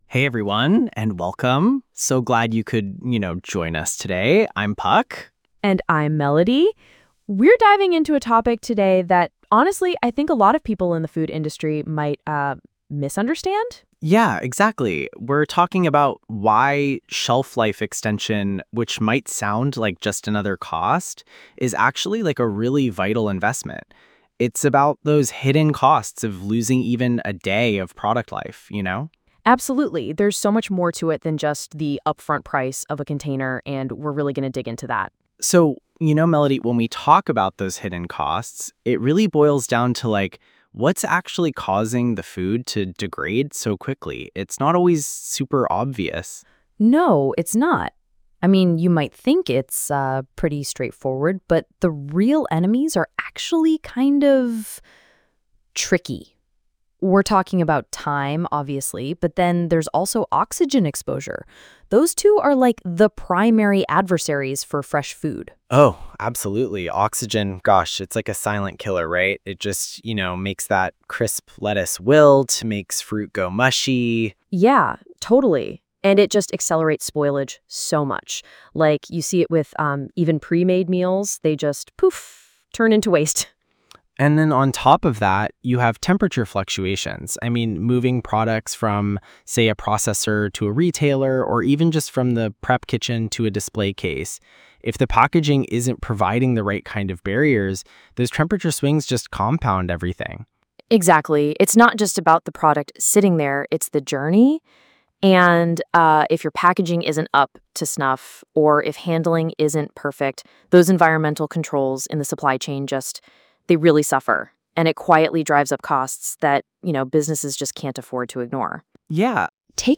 This audio uses AI-generated content and media.